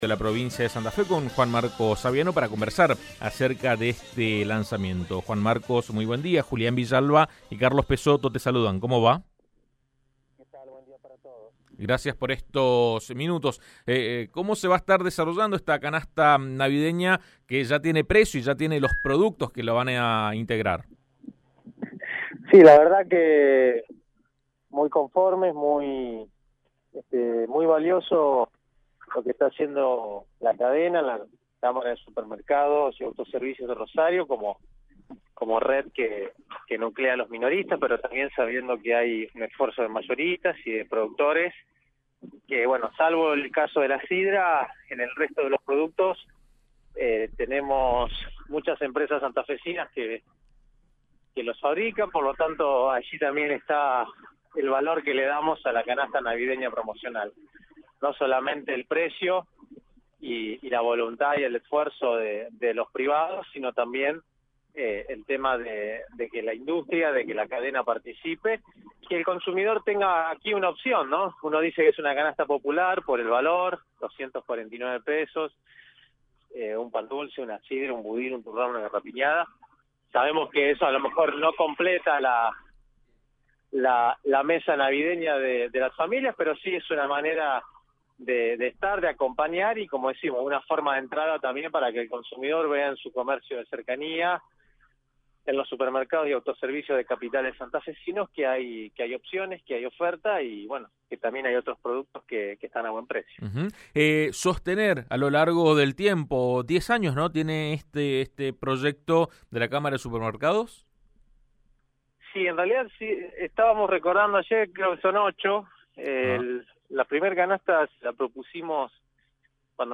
El pack se comercializará en 21 localidades e incluirá un pan dulce, un turrón, una garrapiñada, un budín y una sidra. El secretario de comercio Juan Marcos Aviano lo explicó en AM 1330.